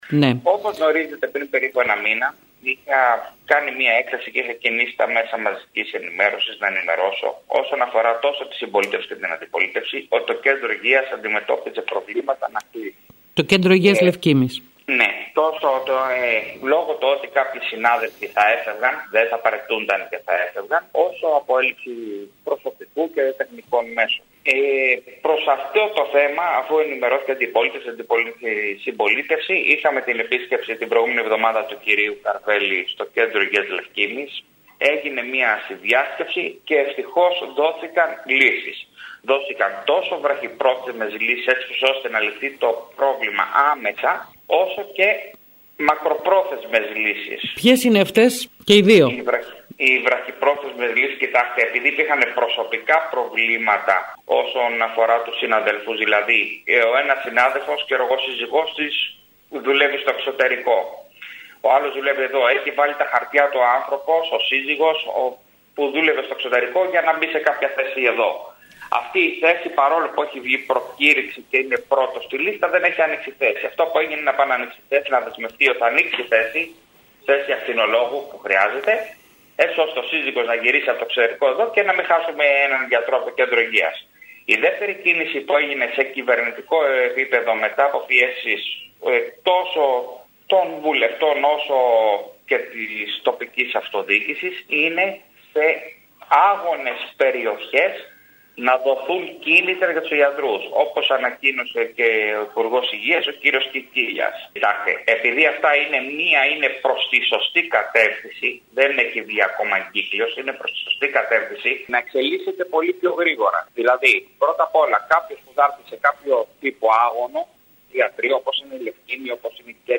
Σε καλό δρόμο είναι το θέμα της στελέχωσης του Κέντρου Υγείας Λευκίμμης δηλώνει σήμερα μιλώντας στην ΕΡΑ Κέρκυρας ο αντιδήμαρχος Υγείας νότιας Κέρκυρας, Γιώργος Σαγιάς.